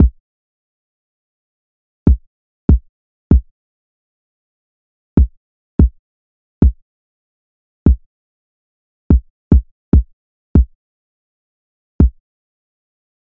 蔑视这一点 踢球 145bpm
描述：陷阱和科幻的结合。沉重的打击和神秘感。
Tag: 145 bpm Trap Loops Drum Loops 2.23 MB wav Key : D